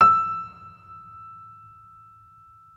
Vintage_Upright